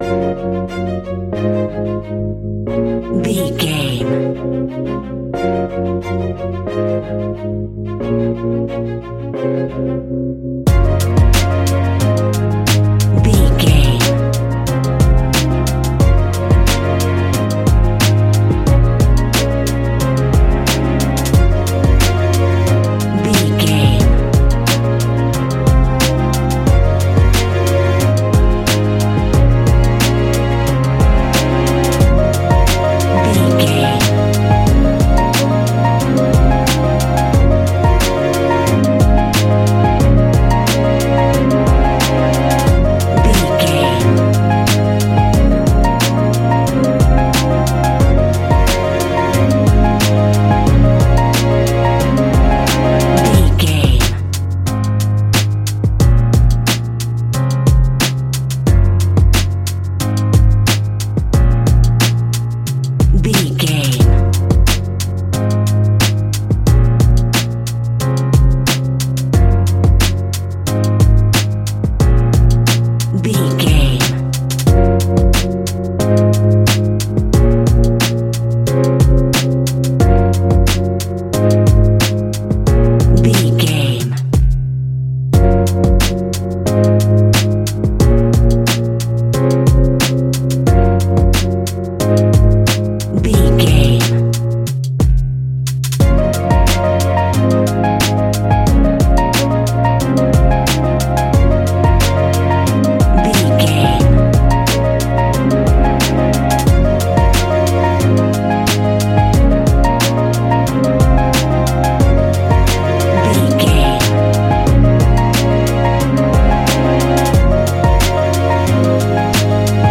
Ionian/Major
C♭
chilled
Lounge
sparse
new age
chilled electronica
ambient
atmospheric
instrumentals